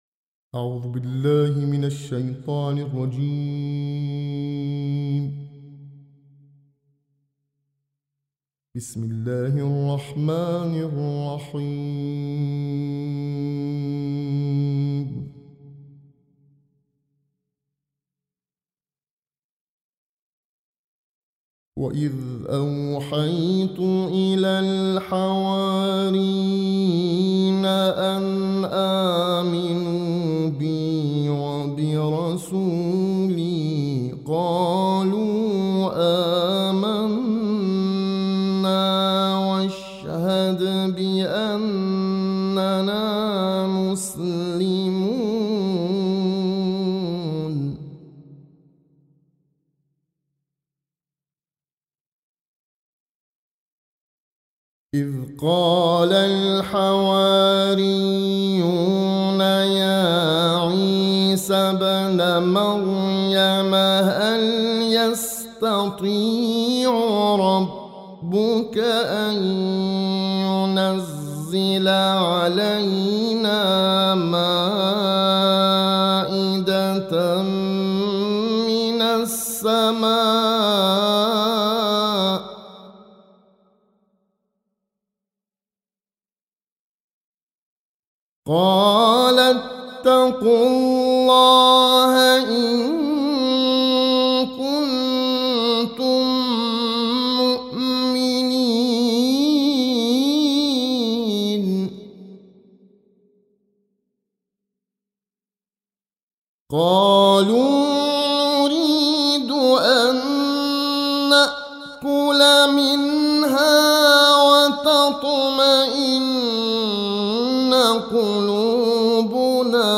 تلاوت
در مسابقه تلویزیونی «إِنَّ لِلْمُتَّقِينَ مَفَازًا»